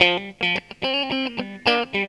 GTR 69 GM.wav